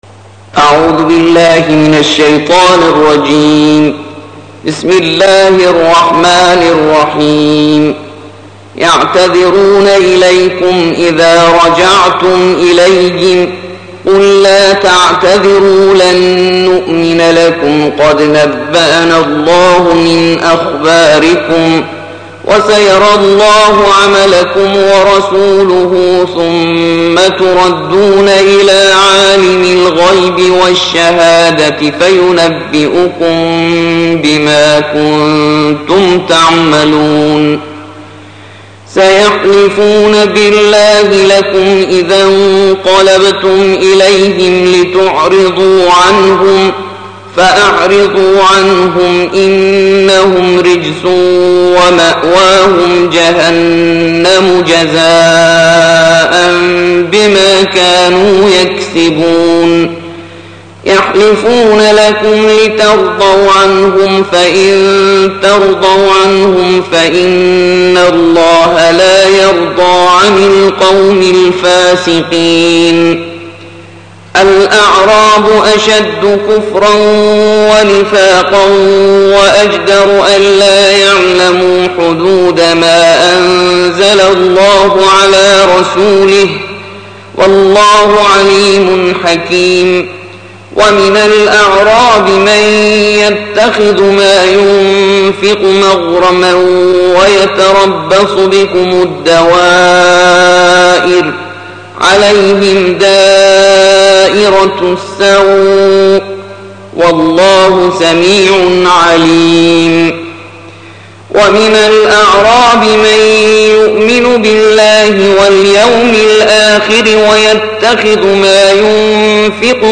الجزء الحادي عشر / القارئ